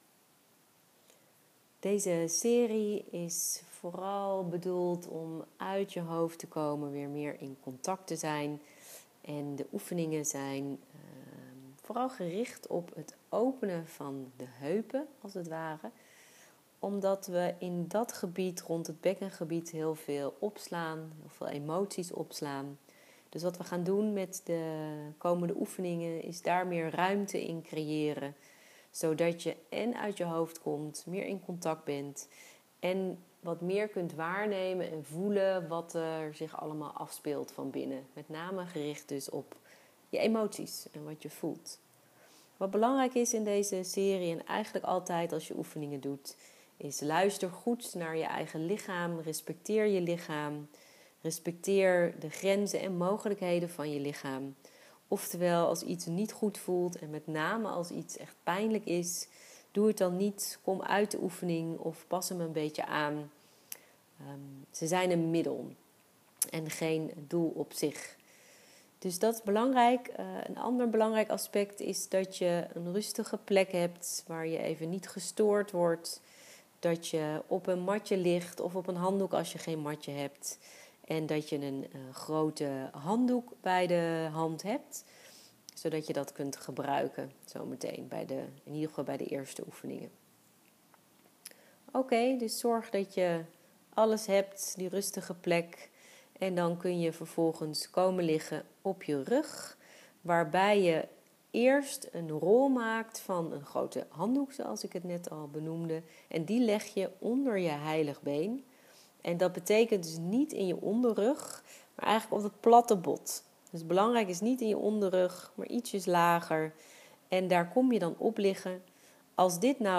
De oefeningen heb ik daarbij ingesproken zodat je kunt luisteren naar mijn stem terwijl je de oefeningen doet.